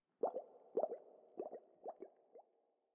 Minecraft Version Minecraft Version snapshot Latest Release | Latest Snapshot snapshot / assets / minecraft / sounds / ambient / underwater / additions / bubbles6.ogg Compare With Compare With Latest Release | Latest Snapshot
bubbles6.ogg